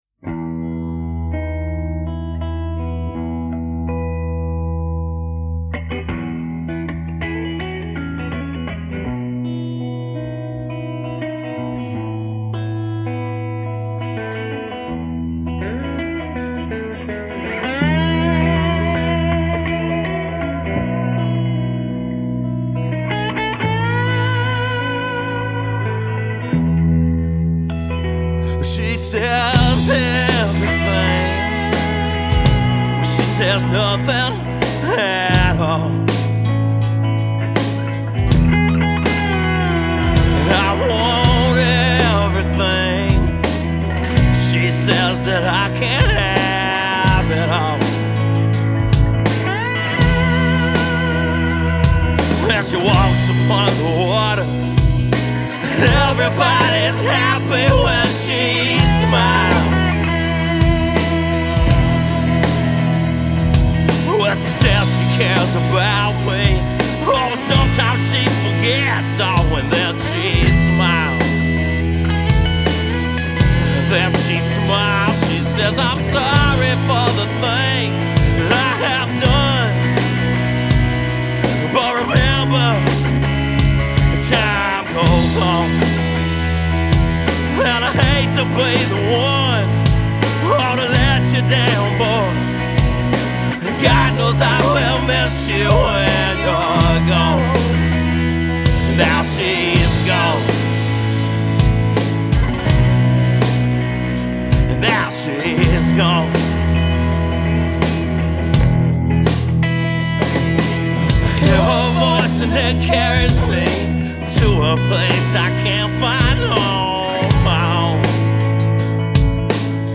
Genre: rock / blues